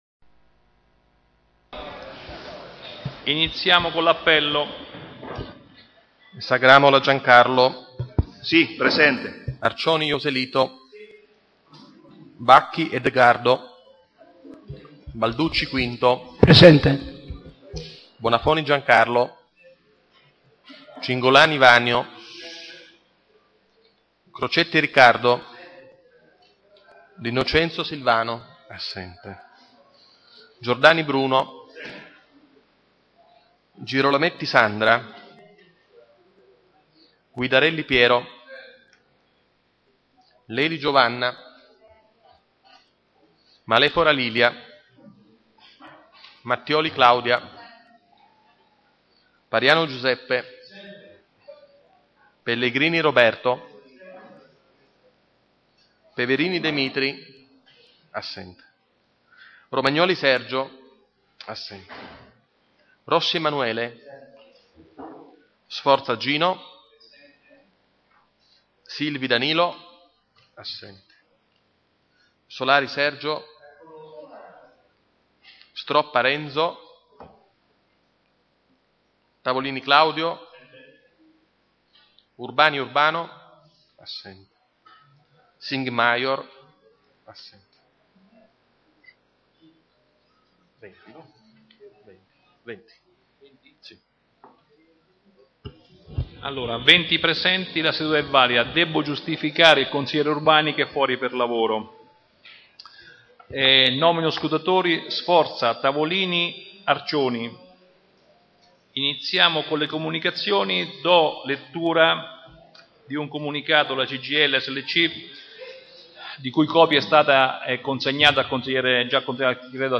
Ai sensi dell'art. 20, comma 7, dello Statuto Comunale e dell'articolo 14 del regolamento consiliare, il Consiglio Comunale è convocato presso Palazzo Chiavelli - sala consiliare giovedì 26 maggio 2016 alle ore 18